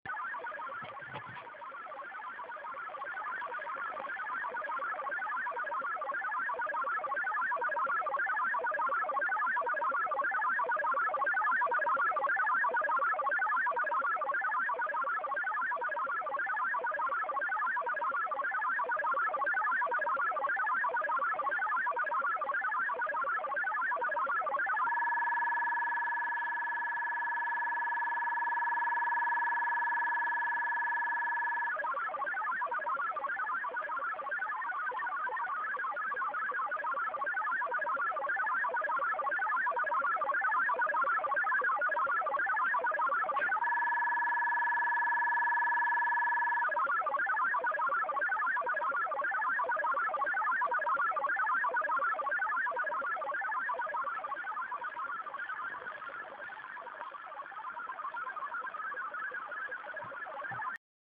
Начало » Записи » Радиоcигналы на опознание и анализ
16.242 МГц - маскиратор?
CROWD36 CIS Сердолик